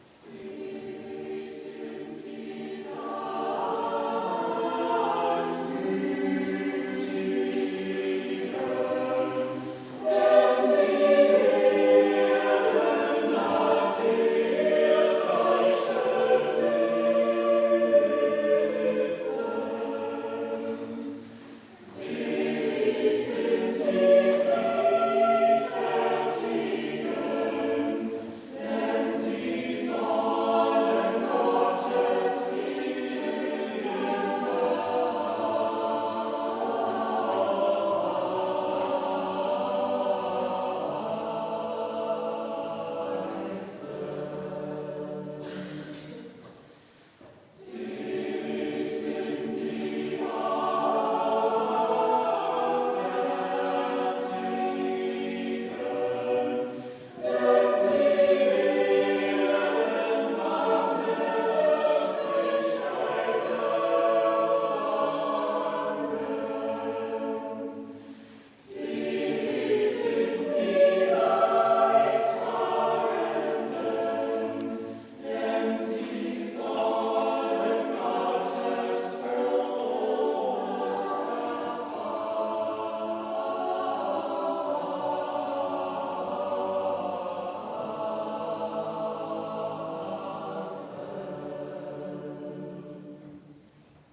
Gottesdienst am 13.03.2022
Selig sind... Chor der Ev.-Luth. St. Johannesgemeinde
Audiomitschnitt unseres Gottesdienstes vom Sonntag Reminiszere 2022.